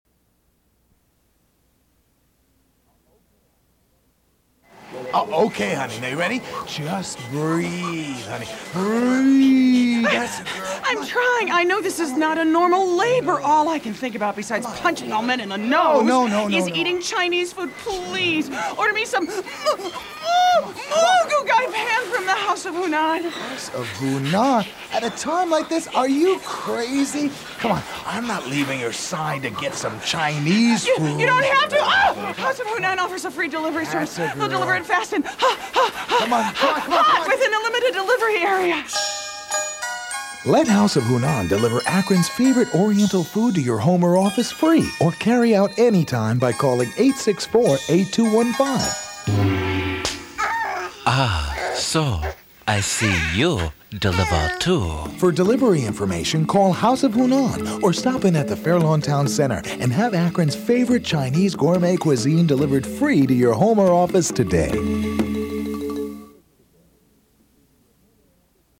These radio commercials have all won Advertising Addy Awards.
House of Hunan Delivery 60 second radio.mp3